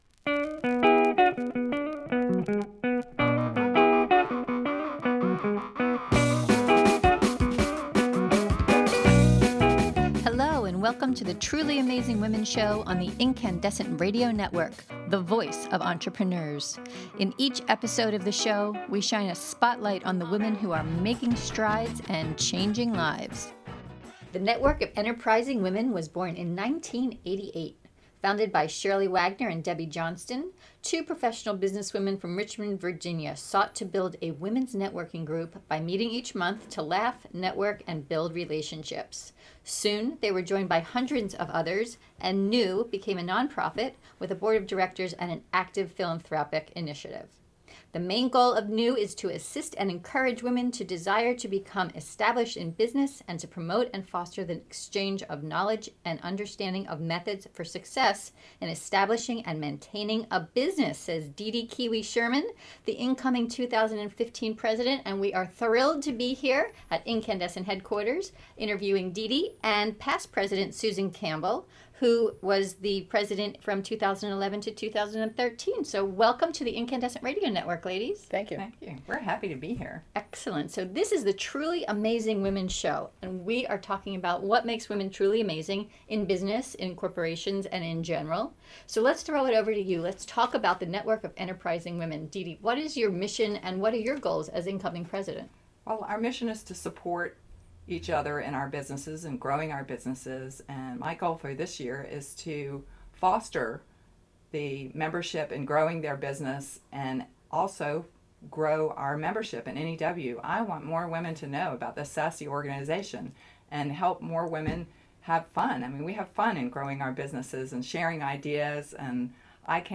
We find in in this podcast interview!